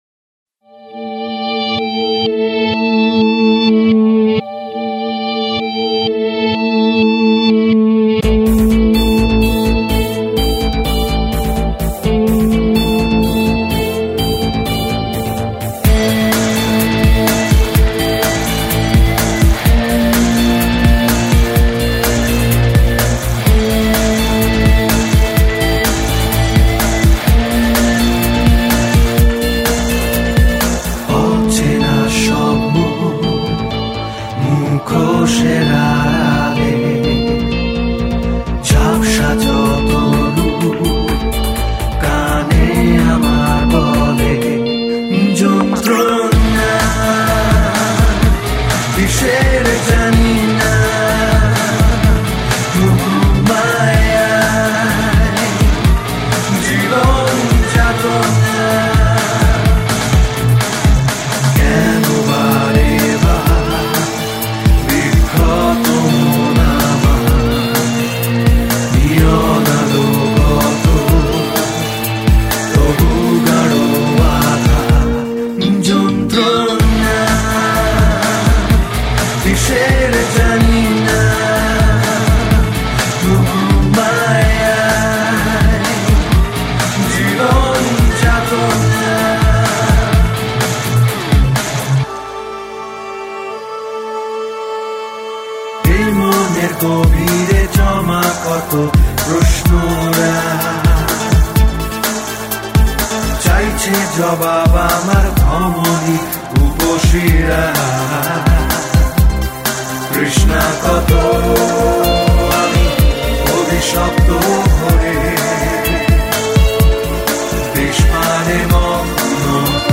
Home » Bengali Mp3 Songs » Medium Quality-64Kbps